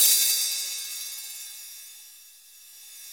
CYM X13 HA0C.wav